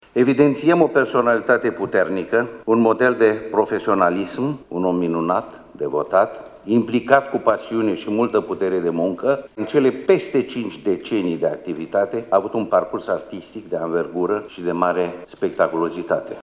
La festivitate au fost prezenţi, pe lângă reprezentanţii autorităţilor locale şi judeţene şi rectorii celor cinci universităţi de stat din Iaşi, precum şi conducerile Teatrului Naţional şi Operei Naţionale Române.